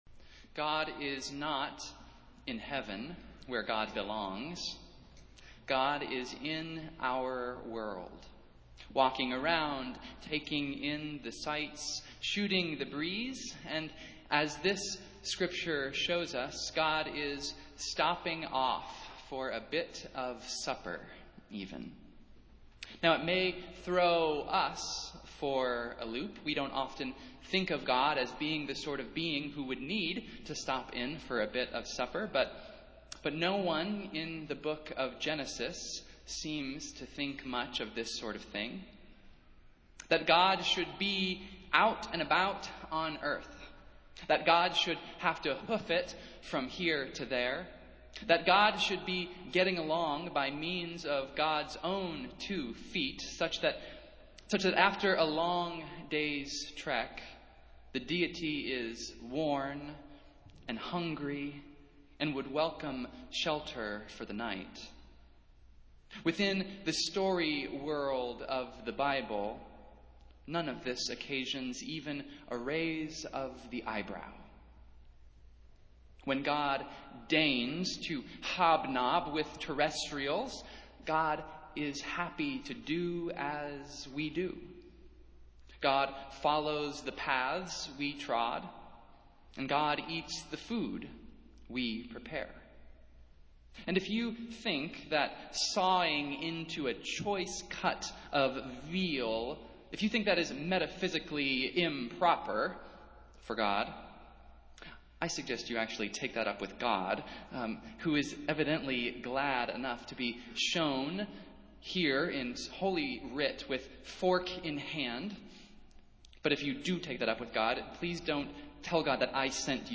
Festival Worship - Second Sunday of Advent